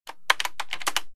Keyboard3.wav